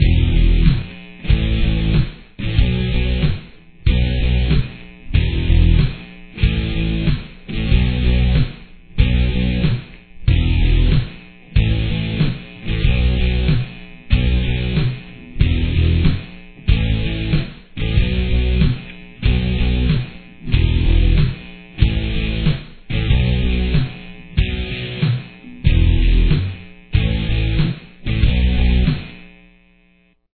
Rhythm